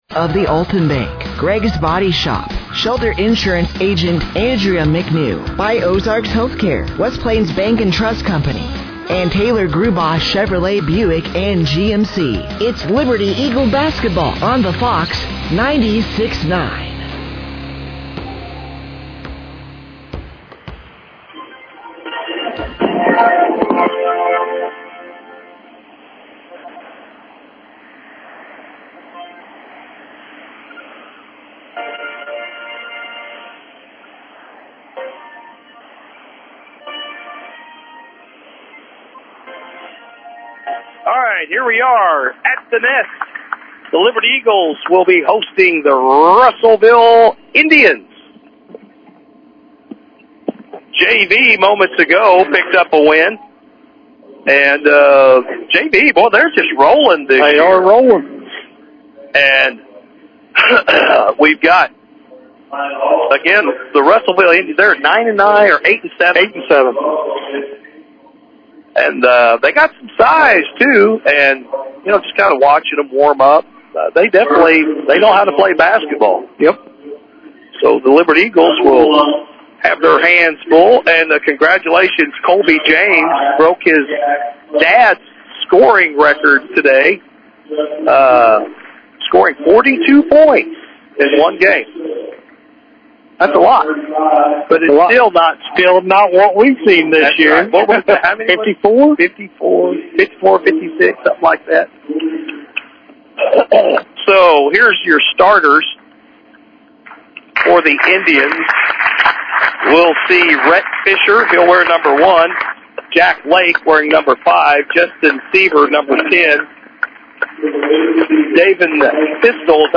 Liberty-Eagles-vs.-Russellville-Indians-1-31-26.mp3